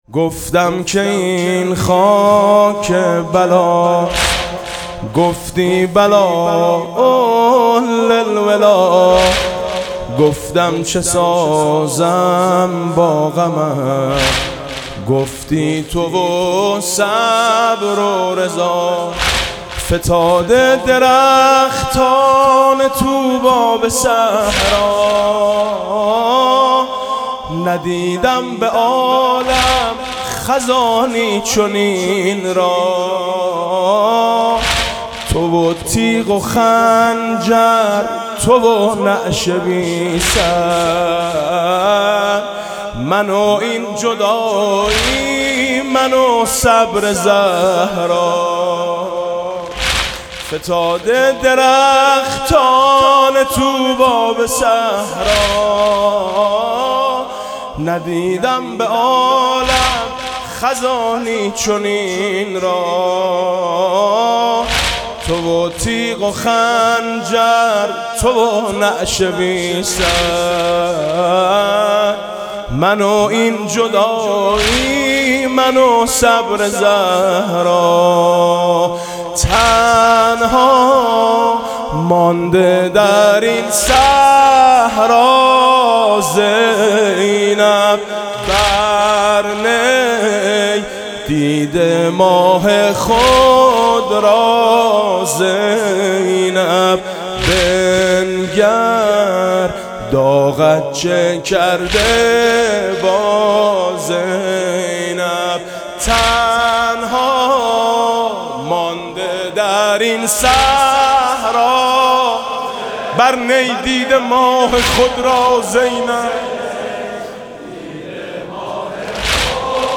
مداحی واحد فارسی و عربی «الخاطرات الموجعات» با صدای میثم مطیعی صوت - تسنیم
به گزارش خبرگزاری تسنیم، فایل صوتی واحد (فارسی و عربی) با نوای حاج میثم مطیعی که در شب پنجم محرم الحرام 1436 در هیأت میثاق با شهداء (دانشگاه امام صادق (ع) اجرا شده است، در ذیل منتشر می‌شود.